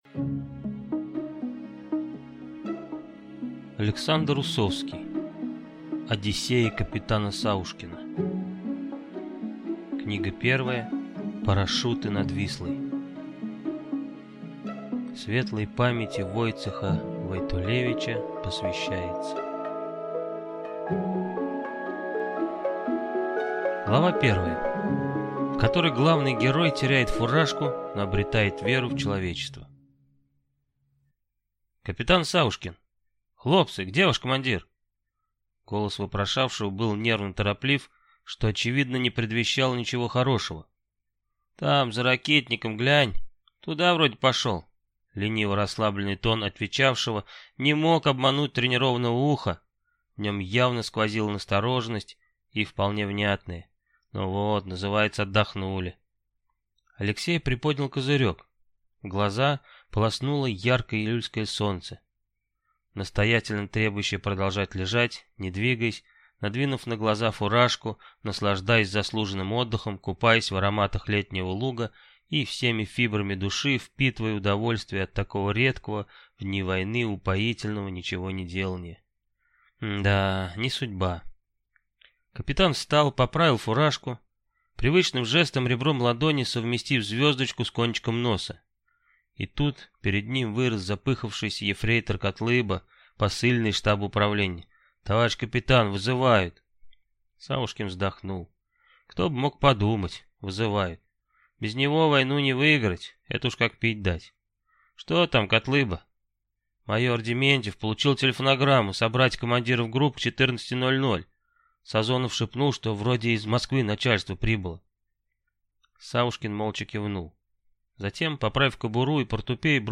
Аудиокнига Парашюты над Вислой | Библиотека аудиокниг